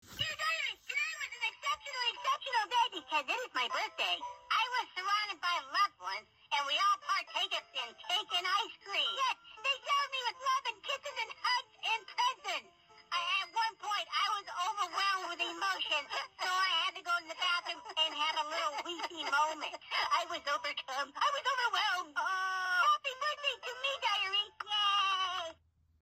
Diary Entry! is a hoops&yoyo greeting card with sound made for birthdays.